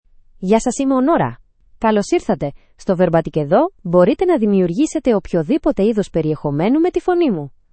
Nora — Female Greek (Greece) AI Voice | TTS, Voice Cloning & Video | Verbatik AI
Nora is a female AI voice for Greek (Greece).
Voice sample
Female
Nora delivers clear pronunciation with authentic Greece Greek intonation, making your content sound professionally produced.